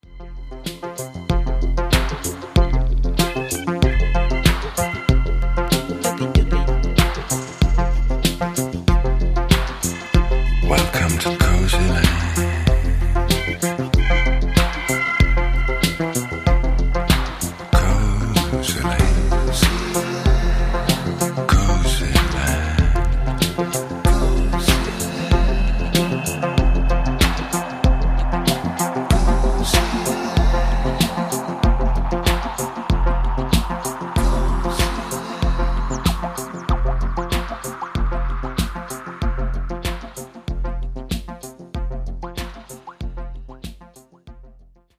Pleasant vibes
complemented with todays vocals, bass and drumbeats.
Disco